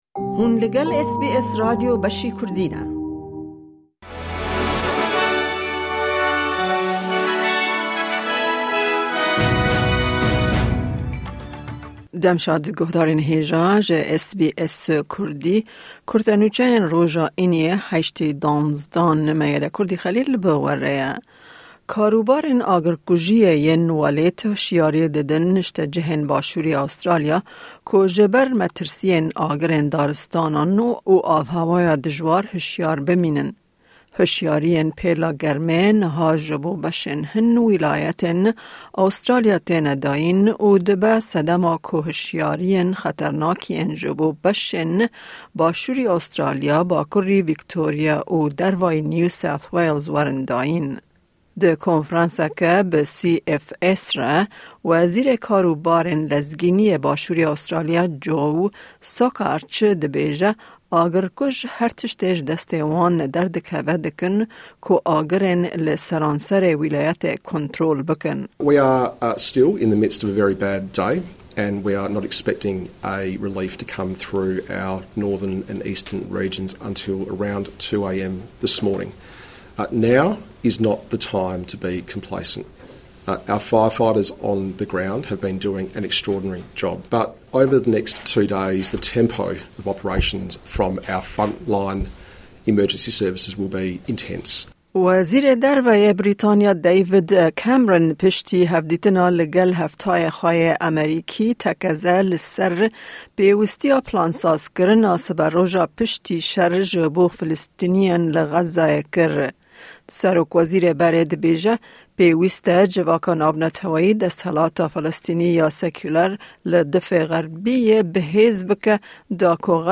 Kurte Nûçeyên roja Înî 08 Dîsêmber 2023